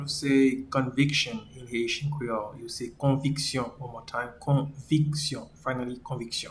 Pronunciation
Conviction-in-Haitian-Creole-Konviksyon.mp3